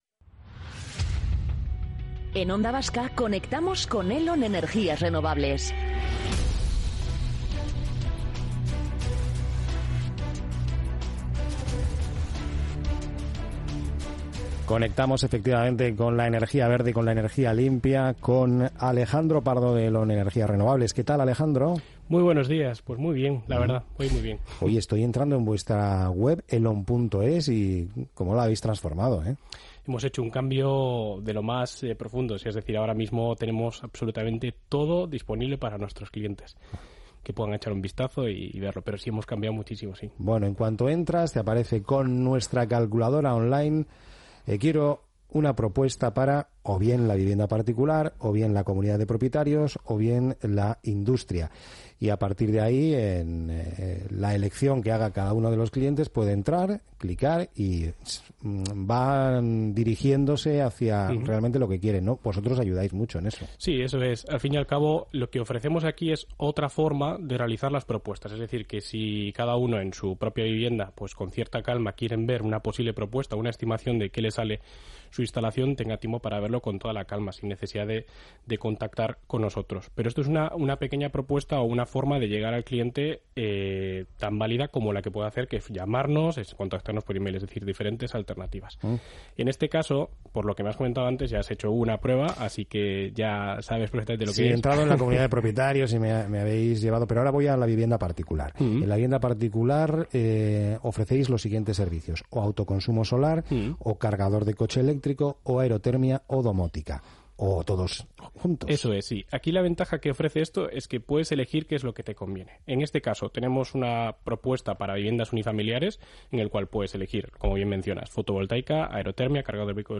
Onda Vasca Bizkaia en directo